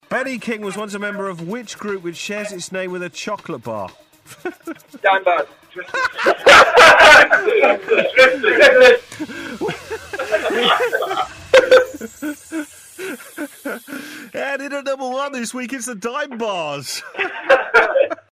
Best Answer Ever On A Radio Quiz 07